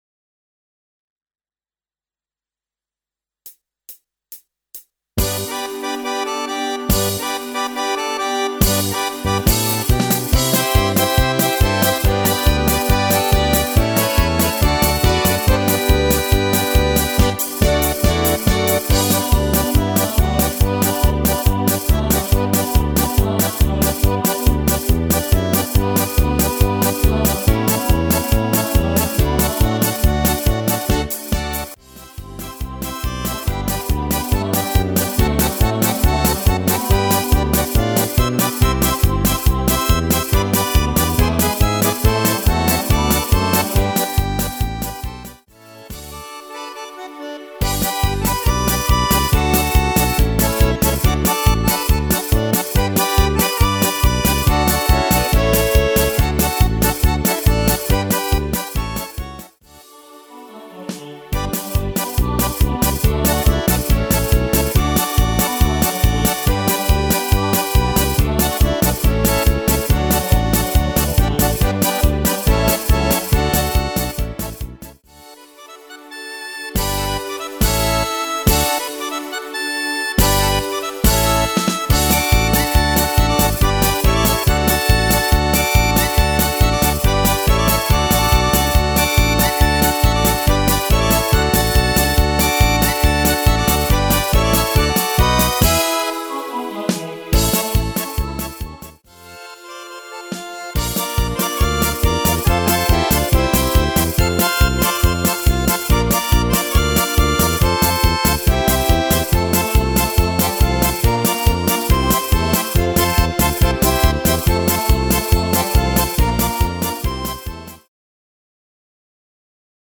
Rubrika: Pop, rock, beat
- směs